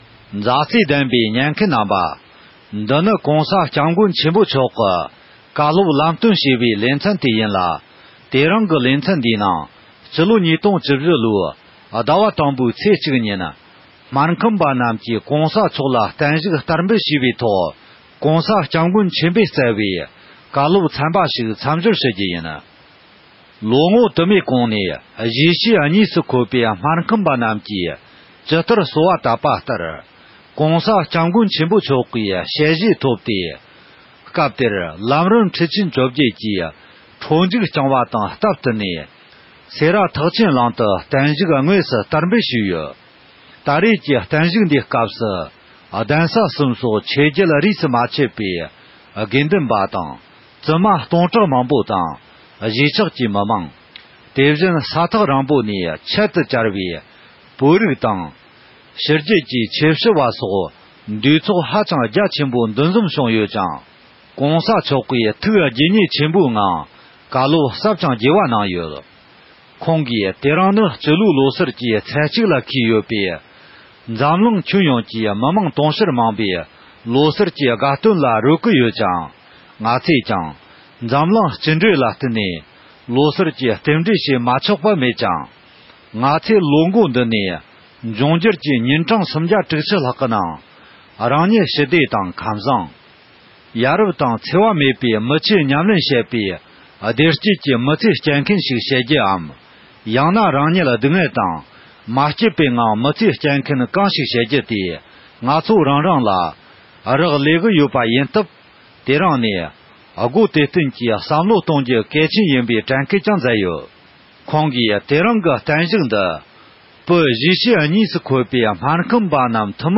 སྨར་ཁམས་པ་ཡོངས་ཀྱིས་ ༸གོང་ས་མཆོག་ལ་བརྟན་བཞུགས་འདེགས་འབུལ་ཞུས་སྐབས་༸མགོན་པོ་གང་ཉིད་ནས་བསྩལ་བའི་བཀའ་སློབ།